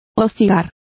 Complete with pronunciation of the translation of vexed.